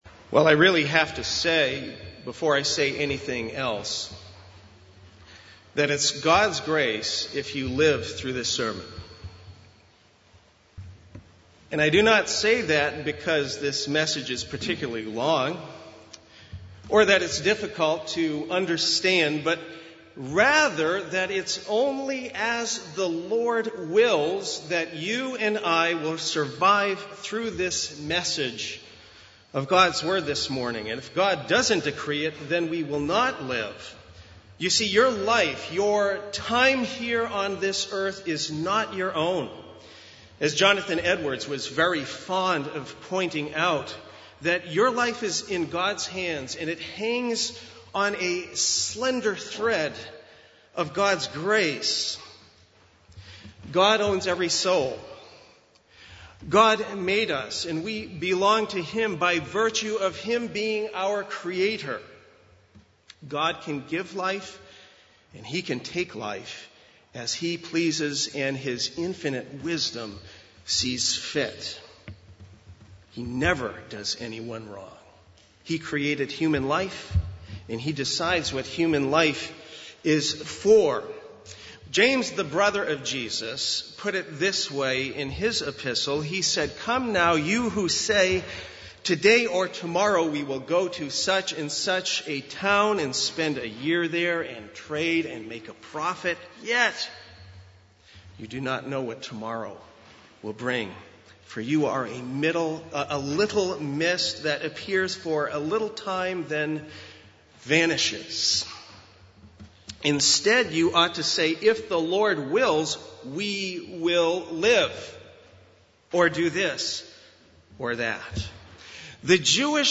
Passage: Ephesians 5:8-17 Service Type: Sunday Morning